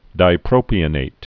(dīprōpē-ə-nāt)